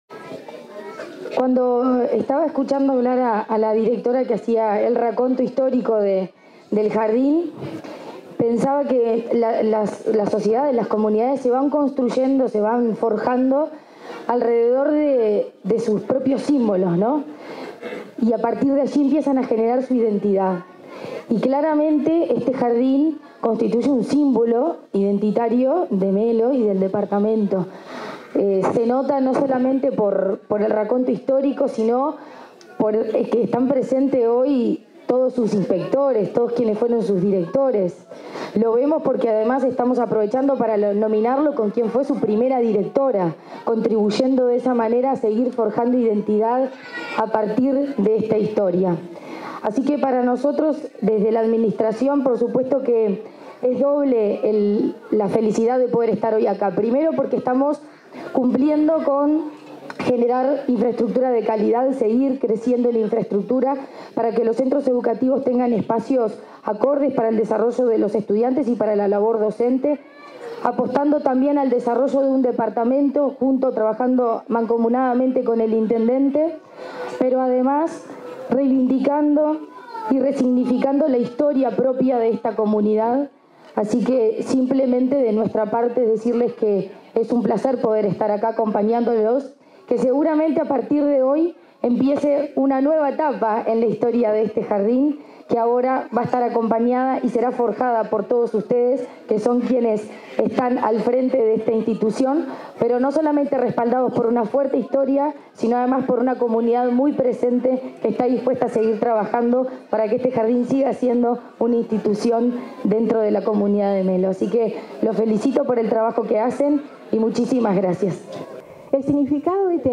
Palabras de la presidenta de ANEP y la directora general de Educación Inicial y Primaria